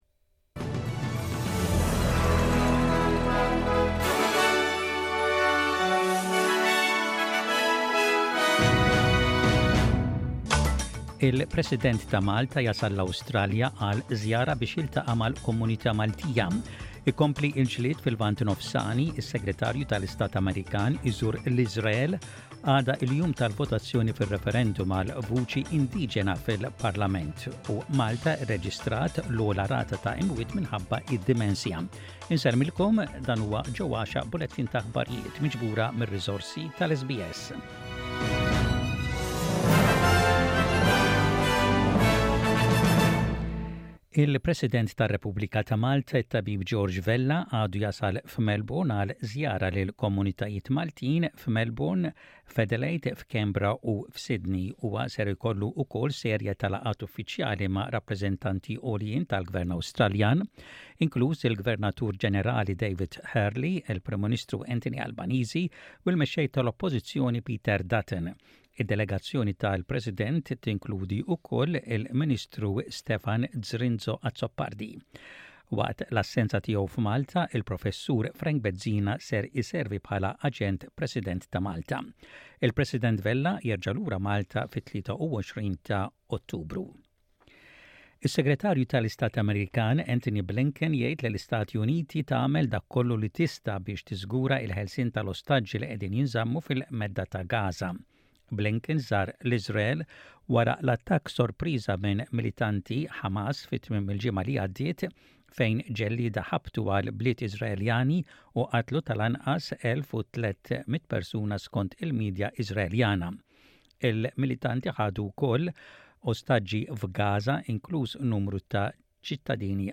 SBS Radio | Maltese News: 13.10.23